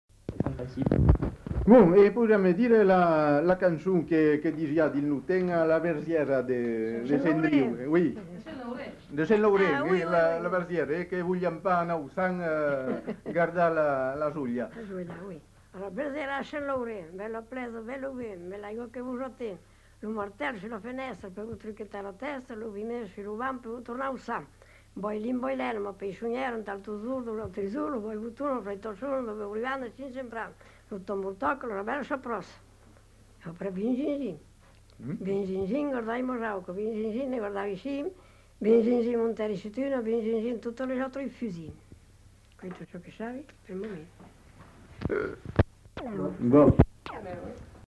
Aire culturelle : Périgord
Genre : chant
Effectif : 1
Type de voix : voix de femme
Production du son : chanté